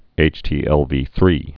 (āchtē-ĕlvē-thrē)